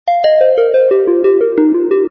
misc_tubularBells00.mp3